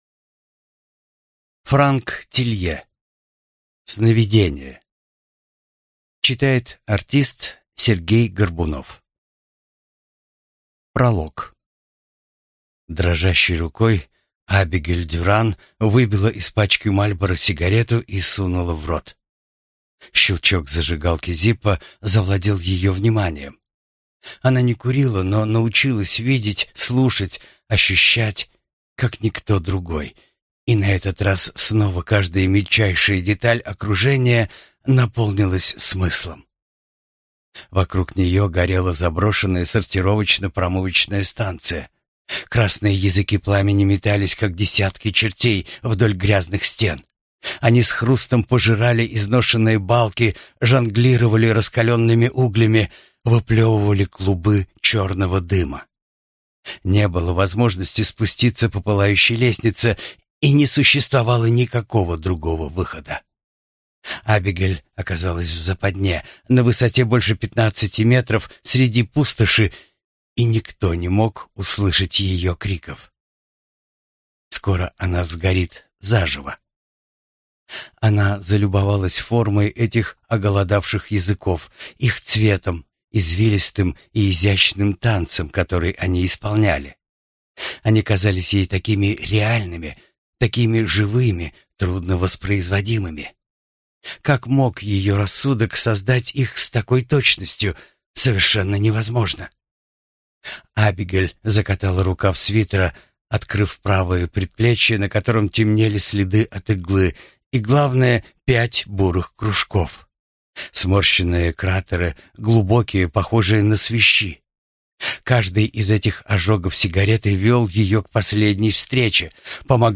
Аудиокнига Сновидение | Библиотека аудиокниг